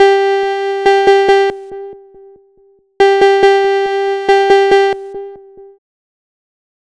Cheese Lix Synth 140-G.wav